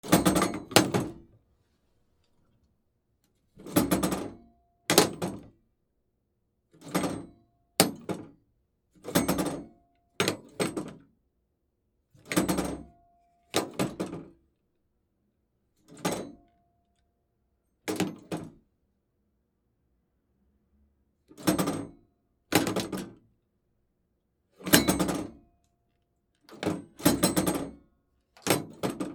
公衆電話 受話器